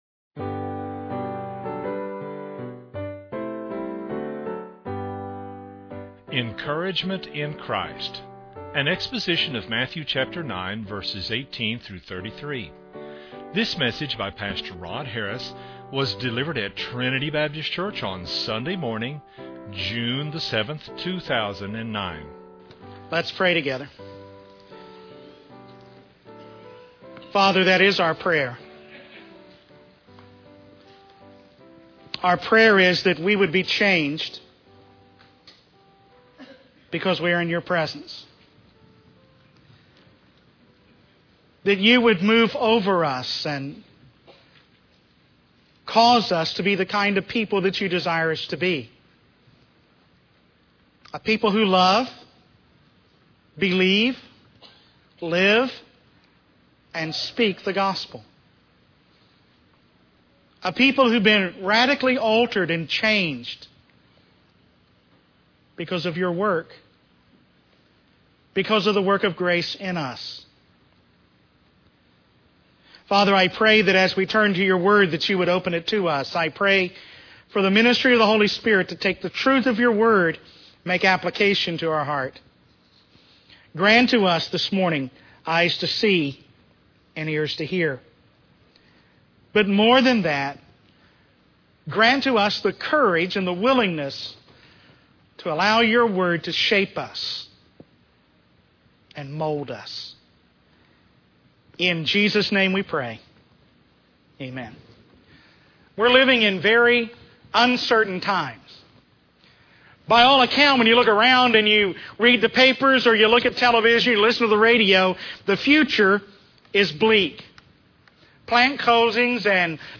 delivered at Trinity Baptist Church on Sunday morning